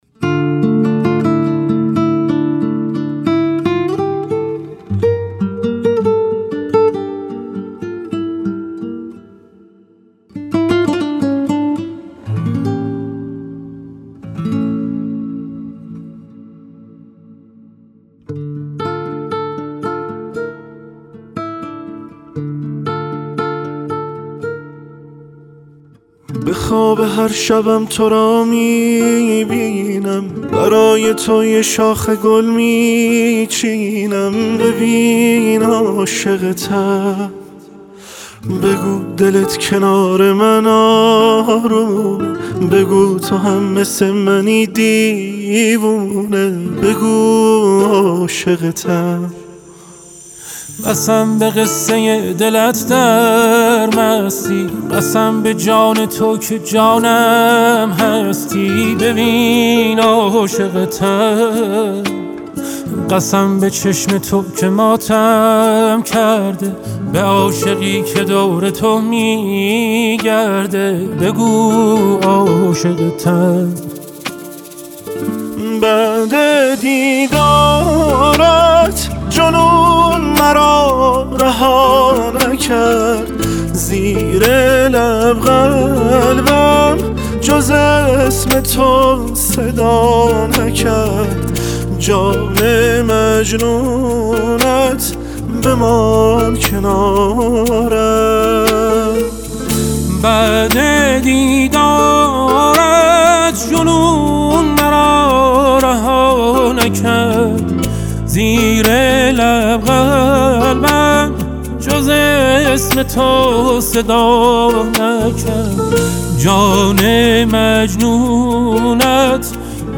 آهنگ پر احساس
این آهنگ یه شاهکارِ عاشقانه‌اس که با ملودی دلنشین